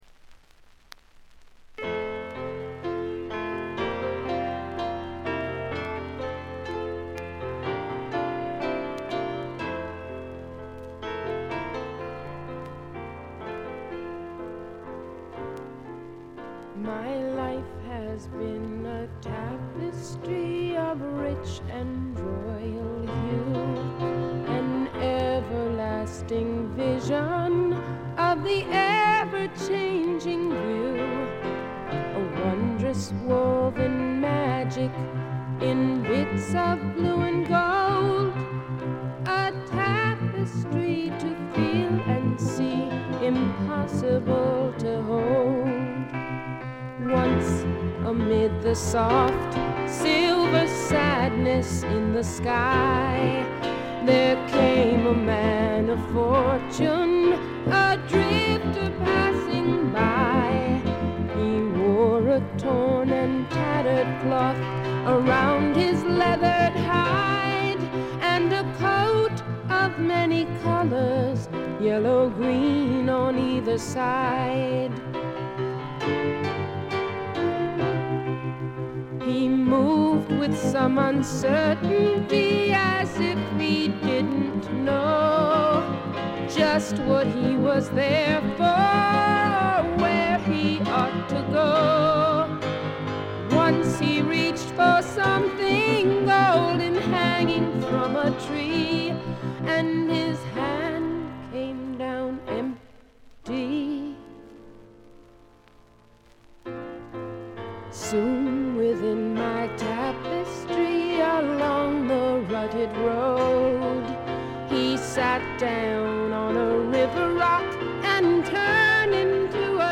全体にバックグラウンドノイズ、チリプチ多め大きめ。
試聴曲は現品からの取り込み音源です。